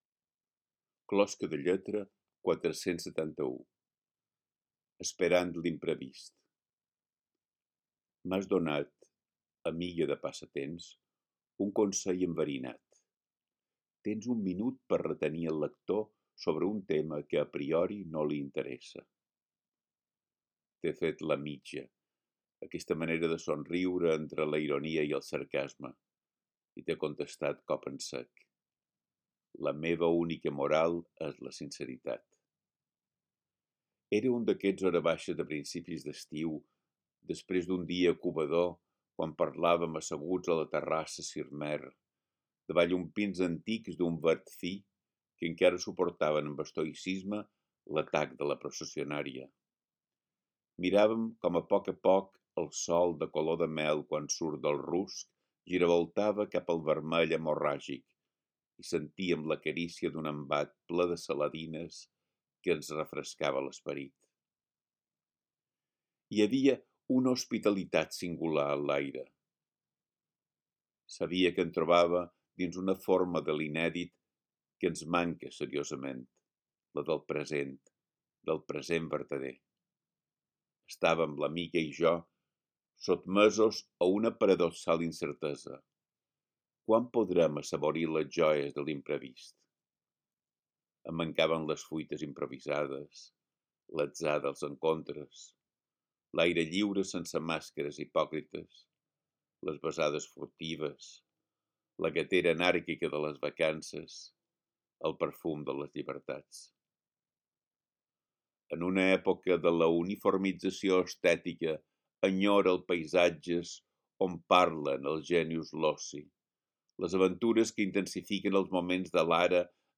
Podeu escoltar el text recitat per Biel Mesquida mateix: